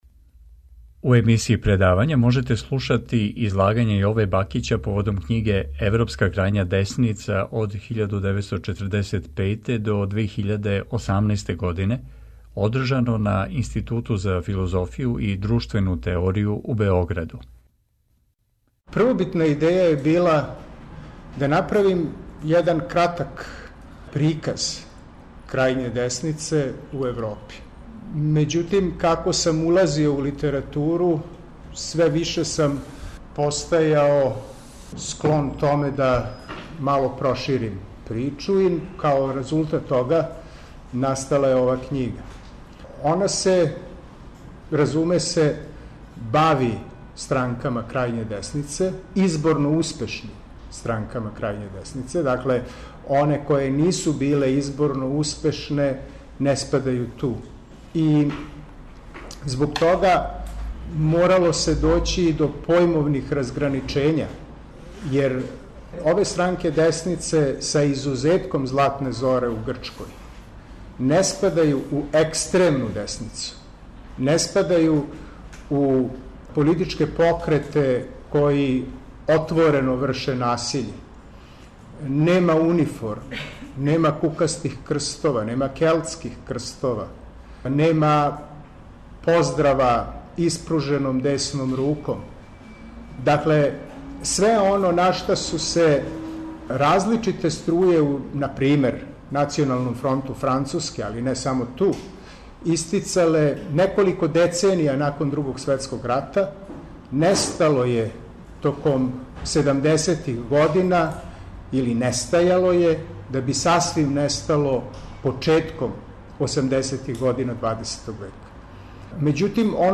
Предавања